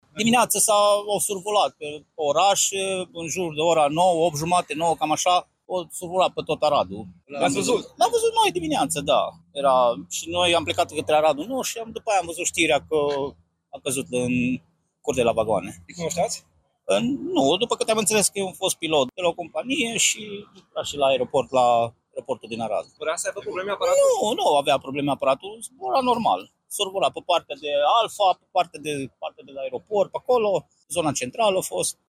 Aparatul de mici dimensiuni a survolat oraşul, de dimineaţă, povesteşte un martor ocular.
01-martor-ocular-avion.mp3